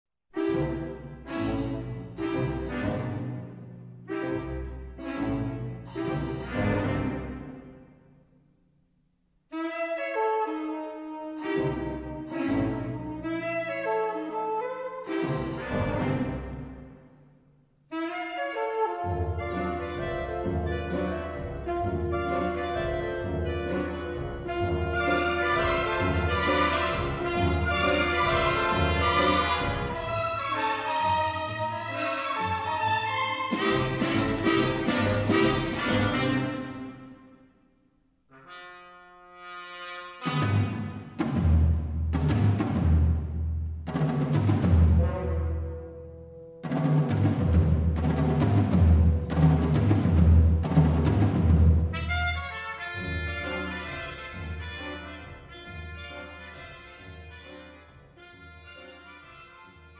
장르 뮤지컬 구분